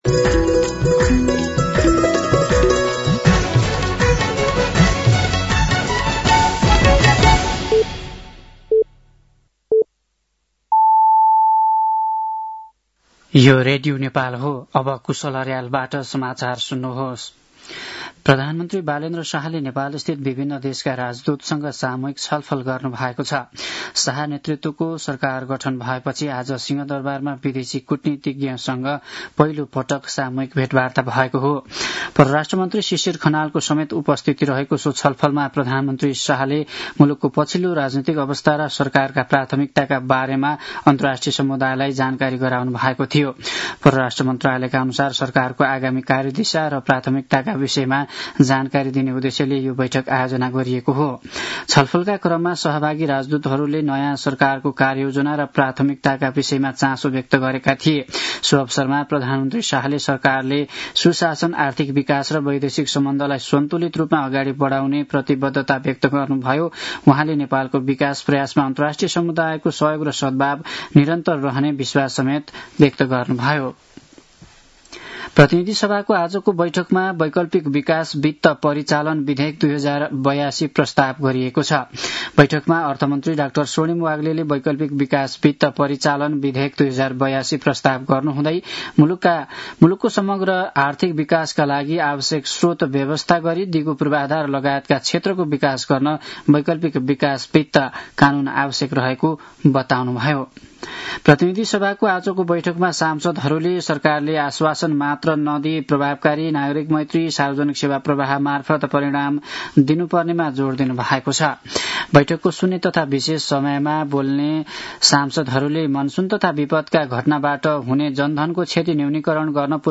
An online outlet of Nepal's national radio broadcaster
साँझ ५ बजेको नेपाली समाचार : २५ चैत , २०८२